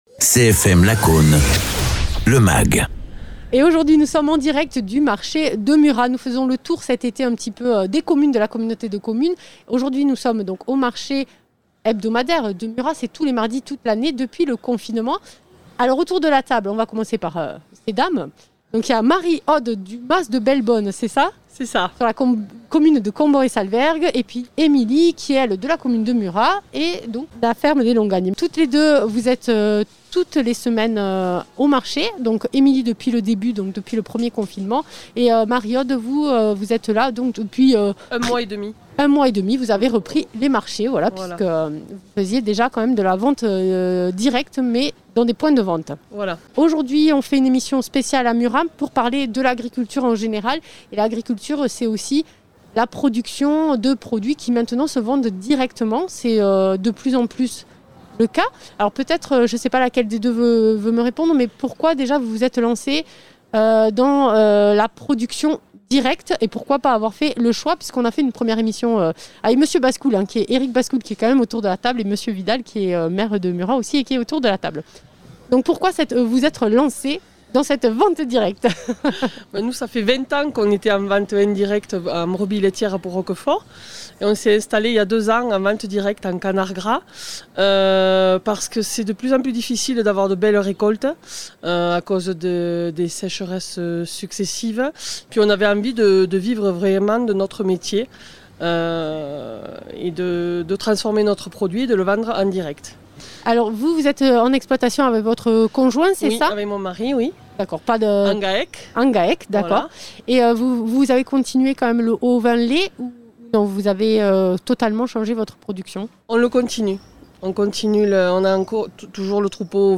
Dans le cadre de nos émissions estivales dans les communes de notre territoire, nous étions au marché de producteurs de Murat-sur-Vèbre. L’occasion de parler de circuit court et de vente directe.
Interviews